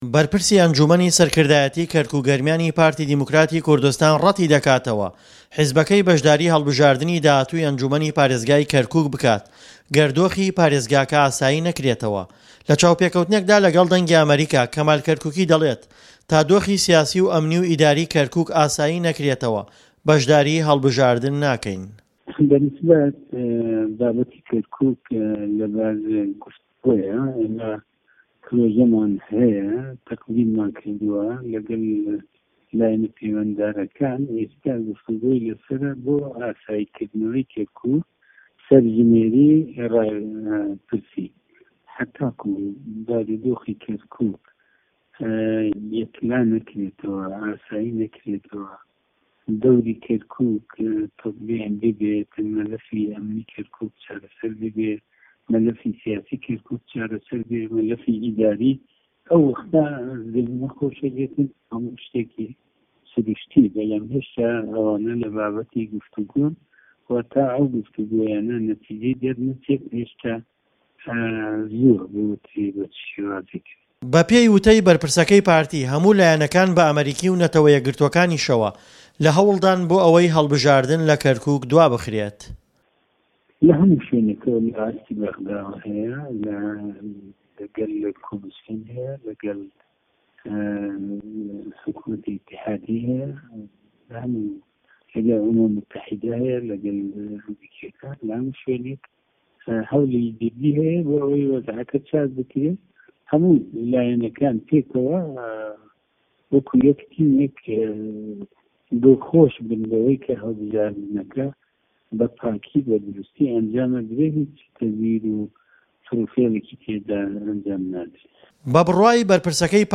وتووێژ لەگەڵ کەمال کەرکوکی